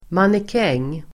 Ladda ner uttalet
mannekäng substantiv, model Uttal: [manek'eng:] Böjningar: mannekängen, mannekänger Definition: person anställd för att bära och förevisa nya kläder manikin substantiv, mannekäng Variantform: även mannequin